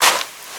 High Quality Footsteps
STEPS Sand, Walk 18.wav